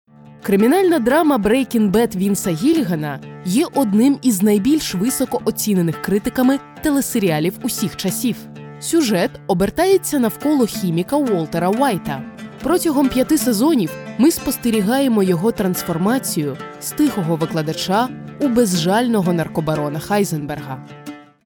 Diep, Natuurlijk, Veelzijdig
Explainer